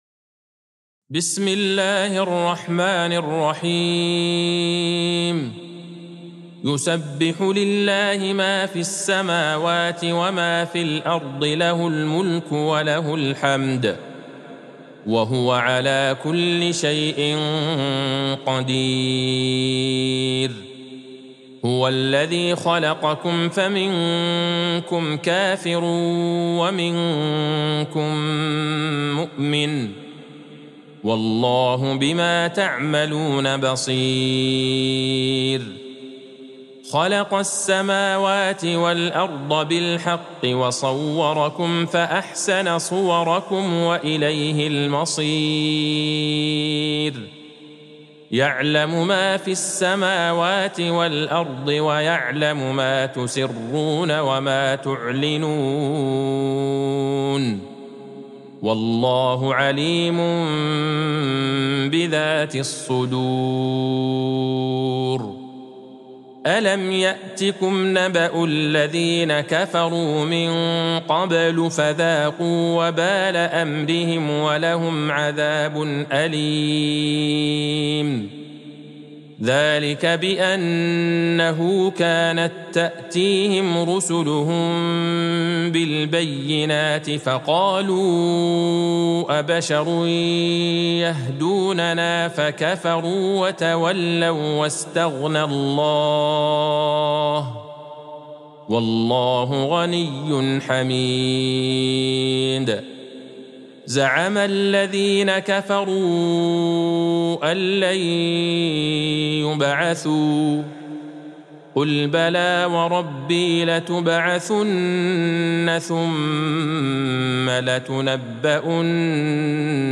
سورة التغابن Surat At-Taghabun | مصحف المقارئ القرآنية > الختمة المرتلة ( مصحف المقارئ القرآنية) للشيخ عبدالله البعيجان > المصحف - تلاوات الحرمين